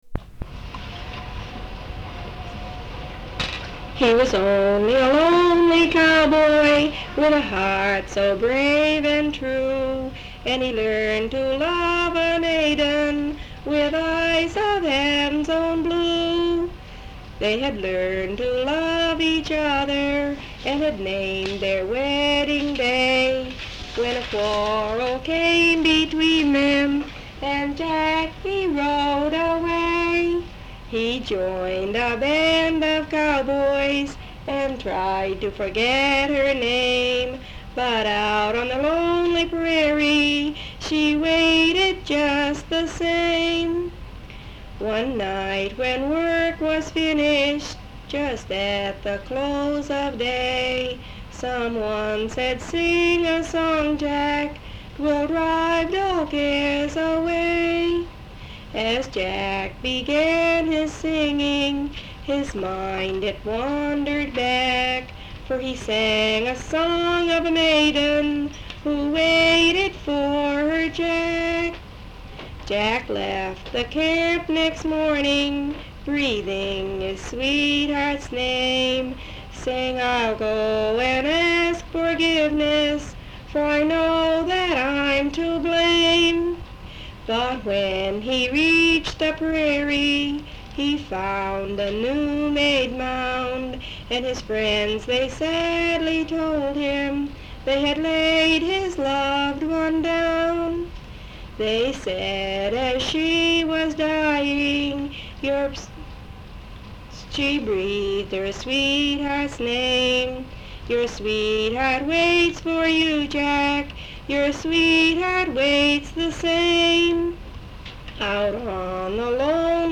Folk songs, English--Vermont (LCSH)
sound tape reel (analog)
Location Marlboro, Vermont